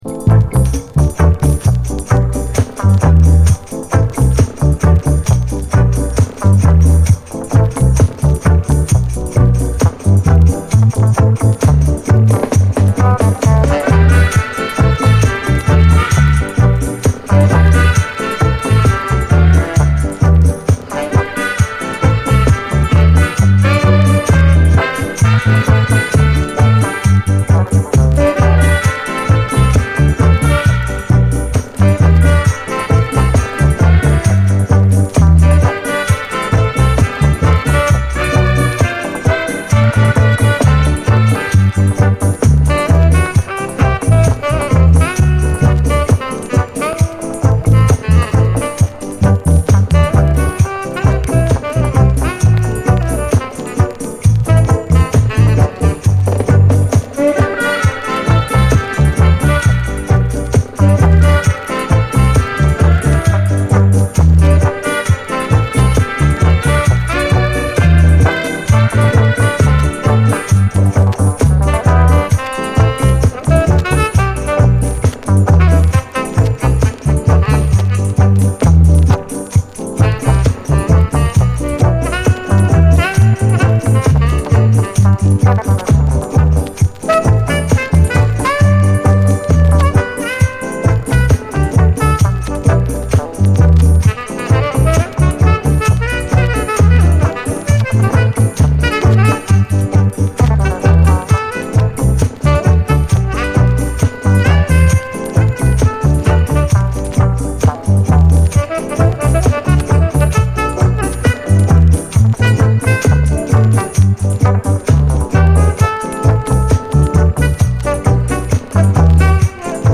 REGGAE, 7INCH